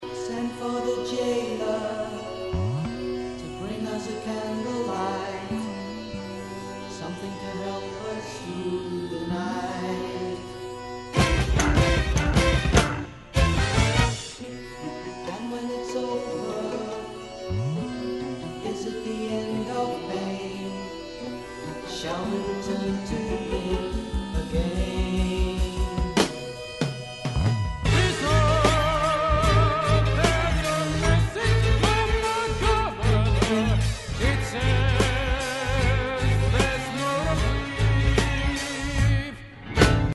Recorded at Command Studios, London